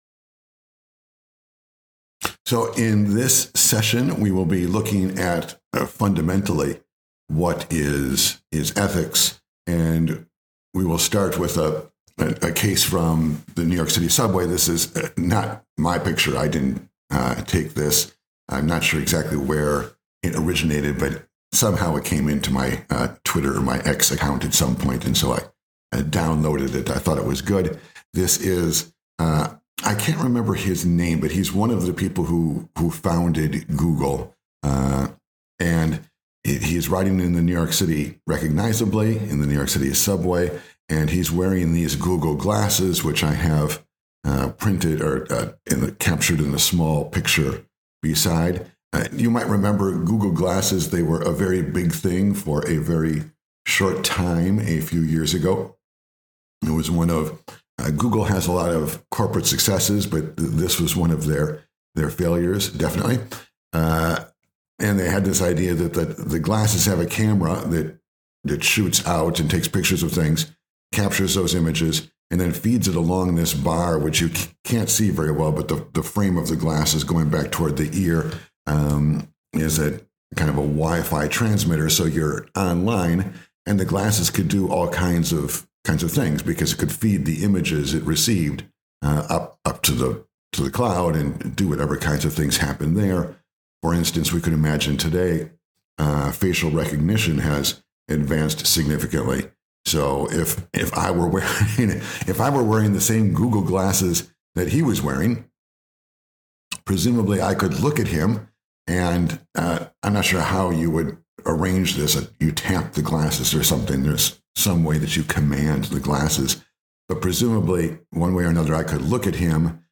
Lecture In this lecture we ask "What is ethics?"
The lecture is meant to include images, but there is a pure audio version here that you may download.